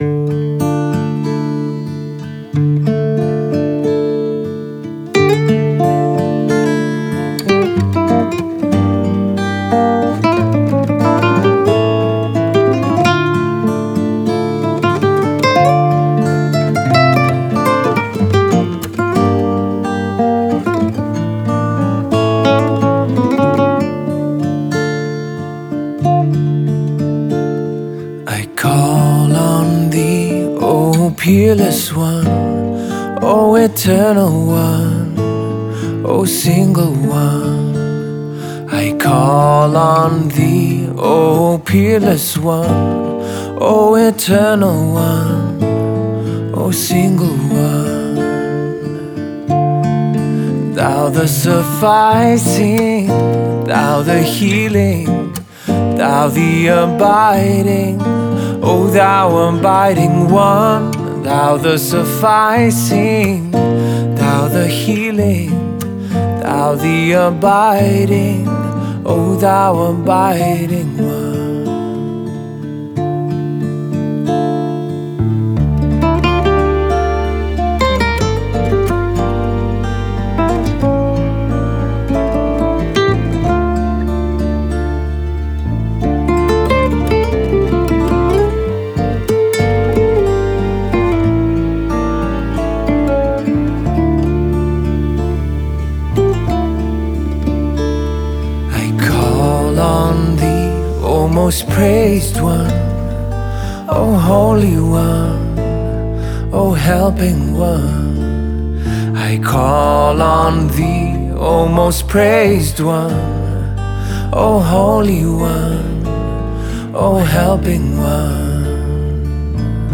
Folk et spiritualité